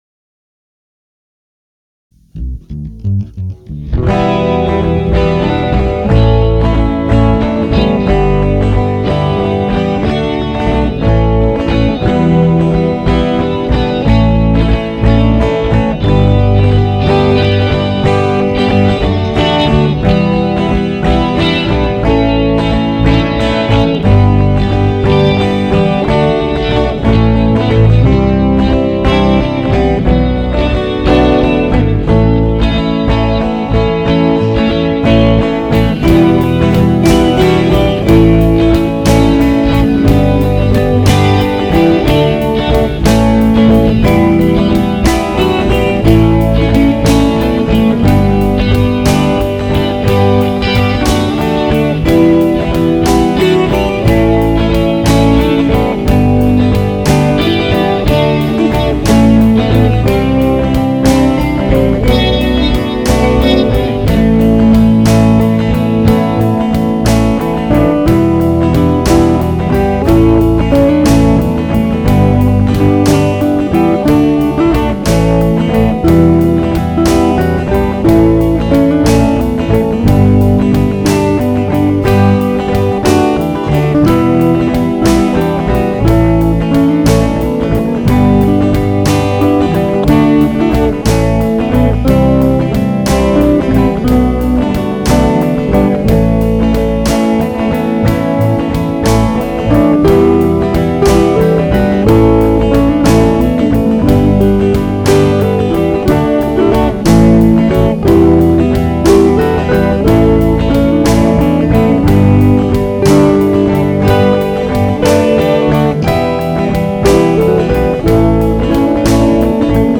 Instrumentals